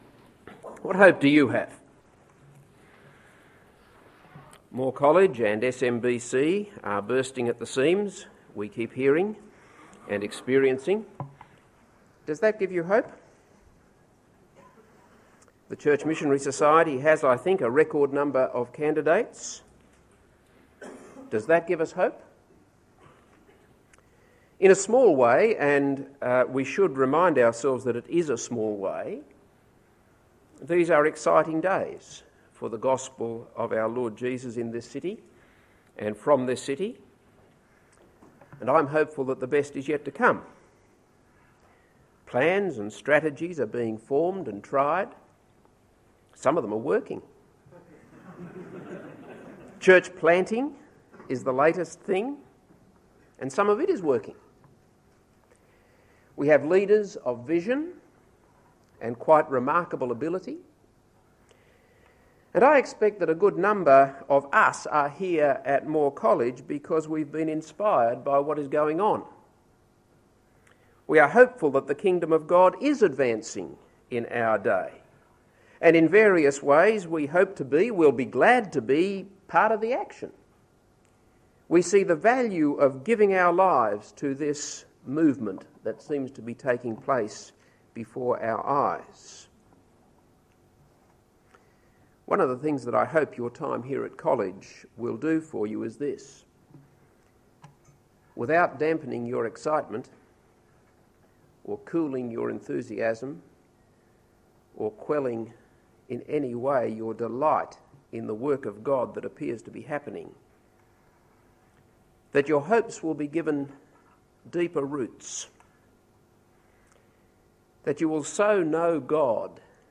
This is a sermon on 1 Samuel 10.